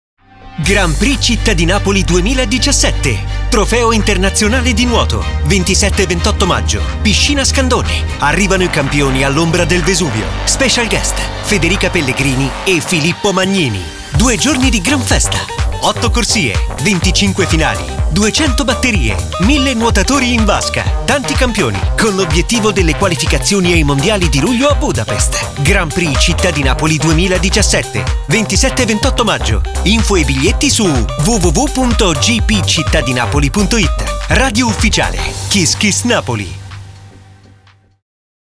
LO SPOT RADIO 2017
Spot-radio.mp3